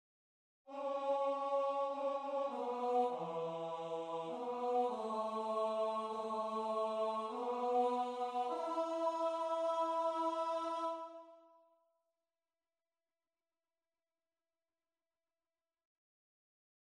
Key written in: F# Major
Type: SATB
Learning tracks sung by